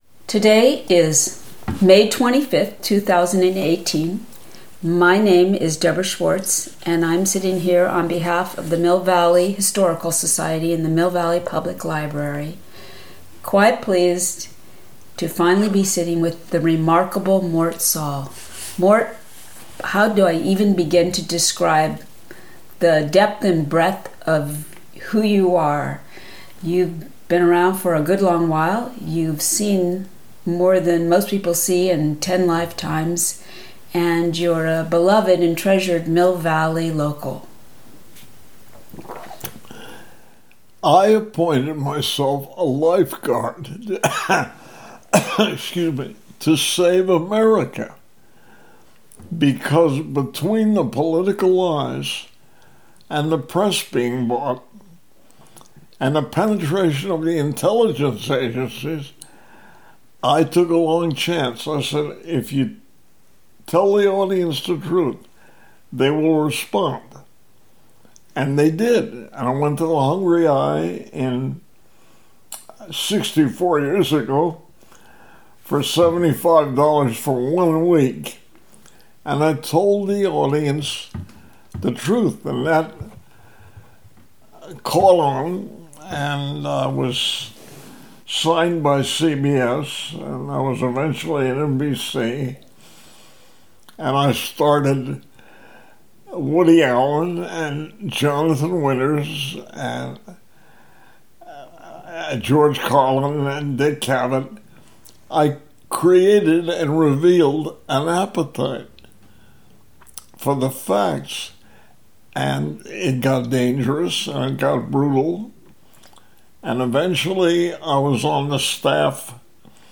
Oral history - Visual, performing, literary arts